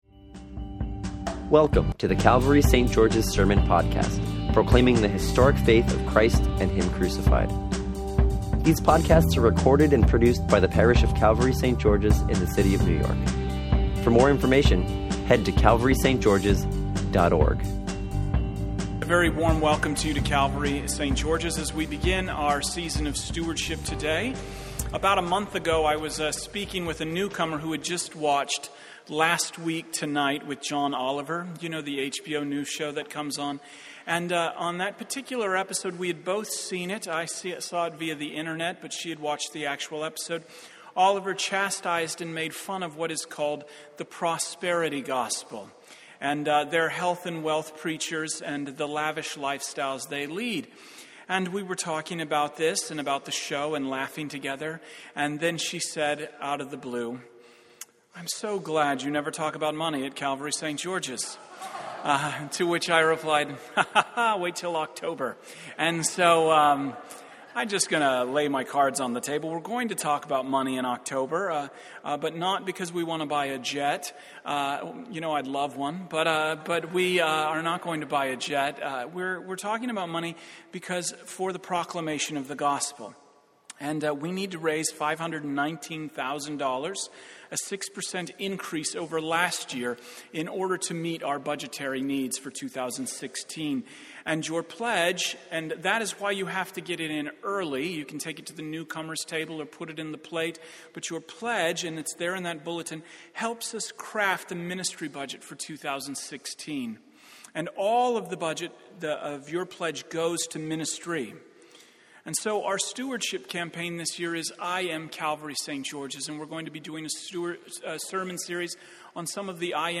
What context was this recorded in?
Venue: Calvary St. George's, NYC Scripture: John 4:7-26